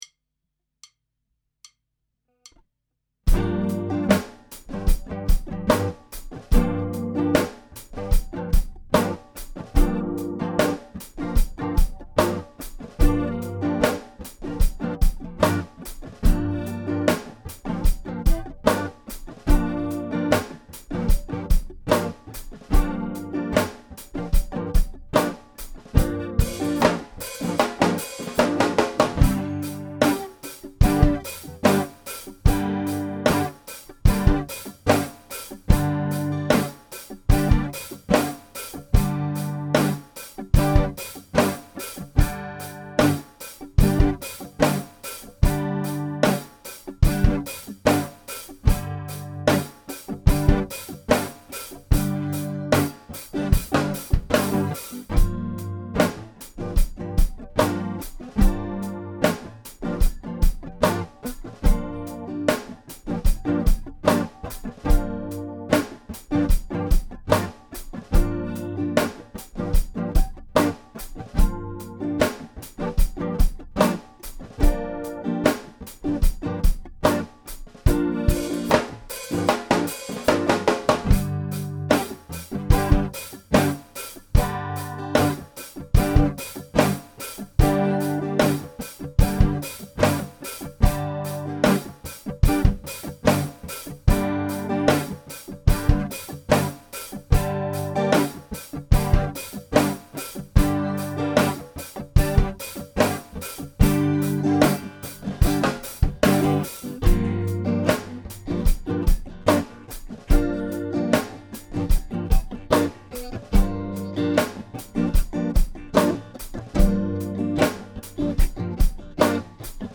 Here is a practice track you can use for practicing bass lines or lead breaks using the G minor pentatonic scale. The rhythm guitar plays two basic rhythms using a Gm7 chord, and then a G minor chord.